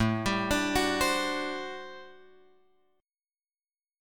A Minor 13th